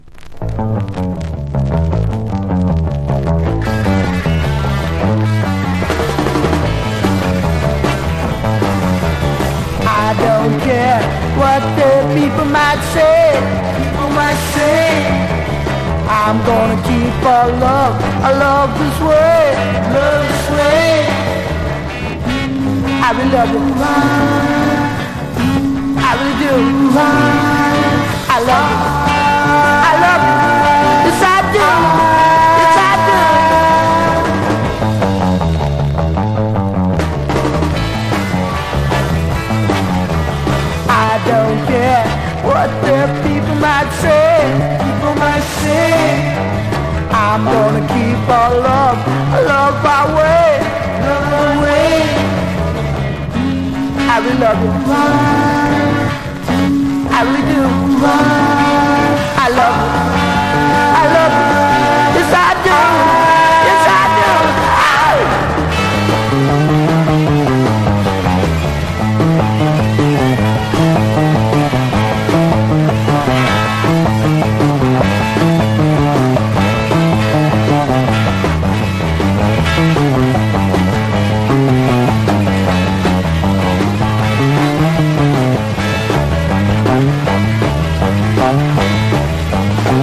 日本初の本格ロック・バンドと評されるGS名バンド